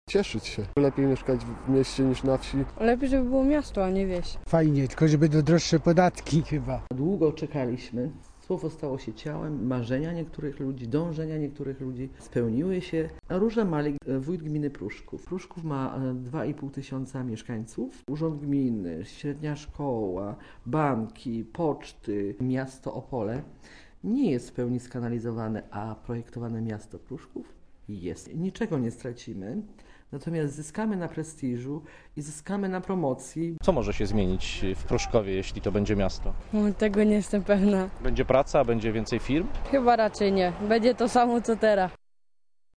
Komentarz audio (296Kb)